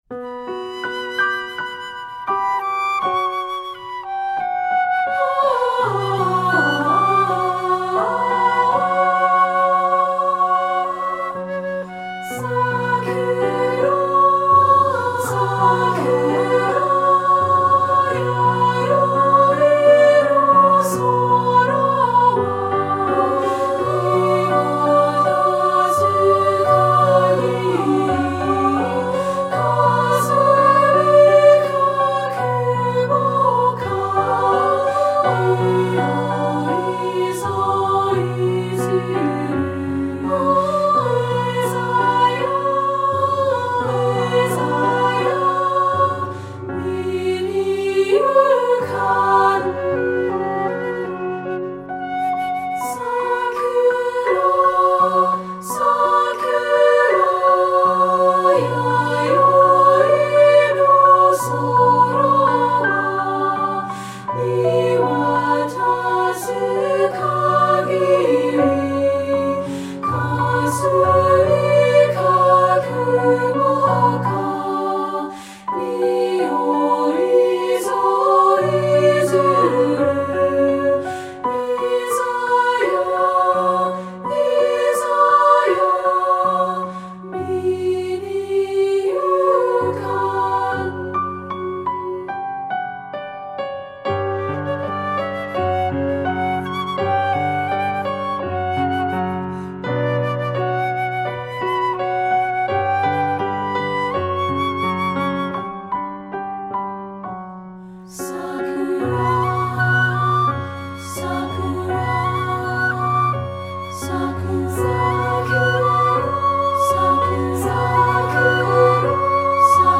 Composer: Japanese Folk Song
Voicing: SSA